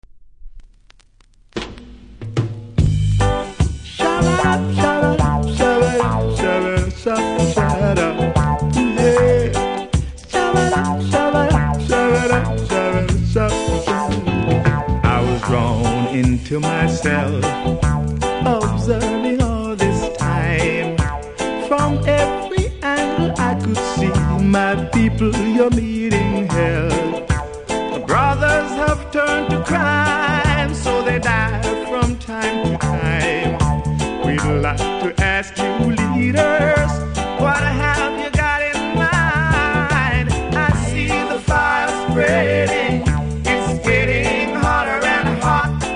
両面ジュークボックス使用キズがあり見た目悪いですが音はそれほど影響されていませんので試聴で確認下さい。